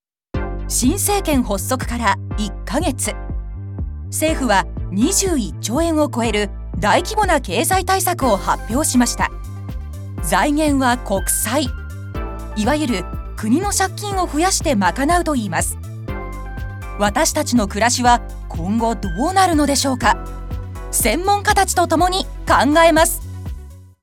報道ニュース